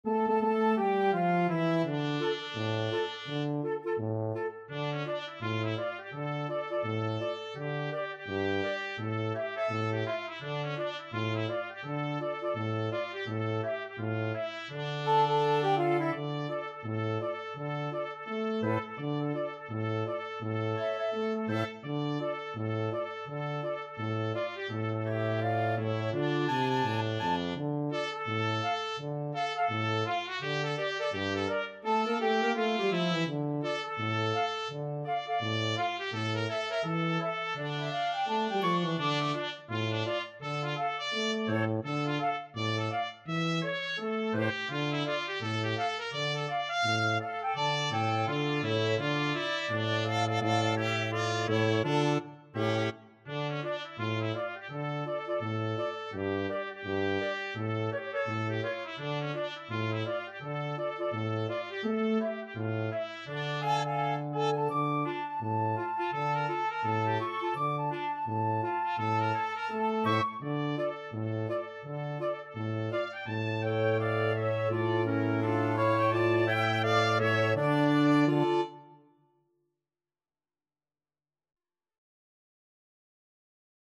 Trumpet
Flute
Clarinet
Baritone
Quick Swing = 84
2/2 (View more 2/2 Music)